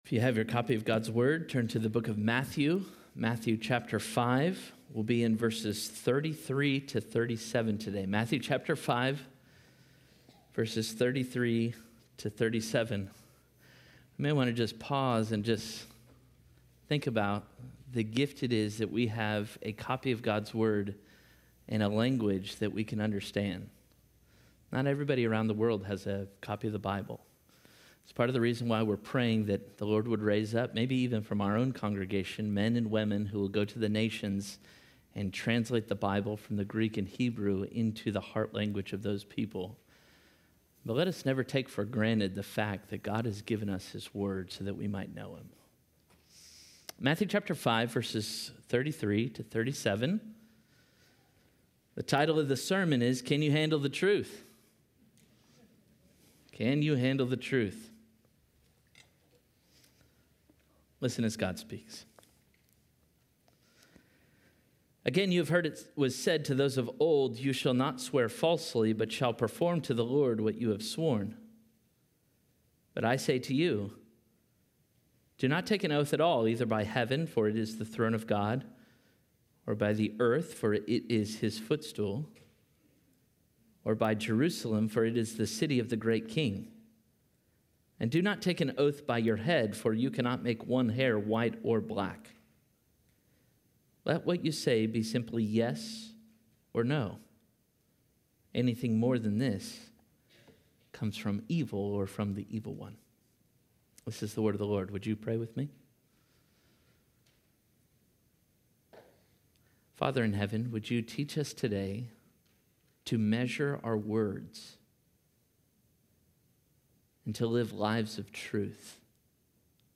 Parkway Sermons Can You Handle the Truth?